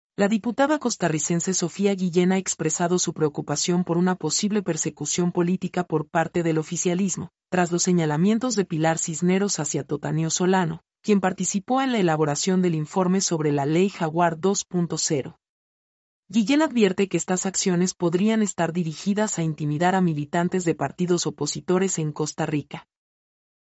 mp3-output-ttsfreedotcom-74-1.mp3